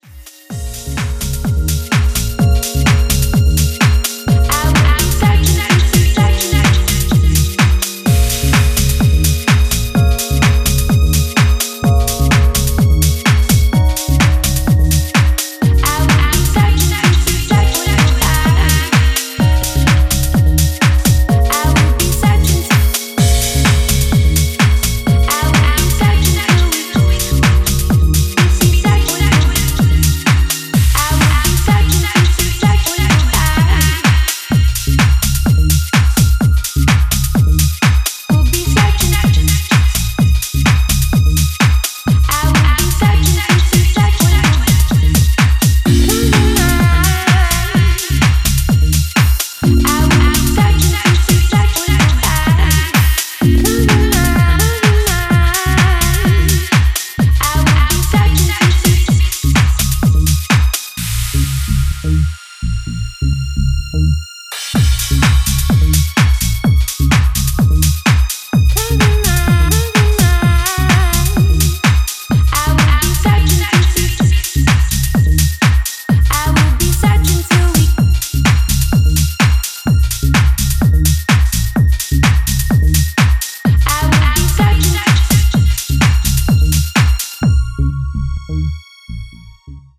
UKレイヴとUSハウスの折衷を試みるような、ピークタイム仕様の意欲作です。
非常に煌びやかなガラージ・ハウスに仕上がっています！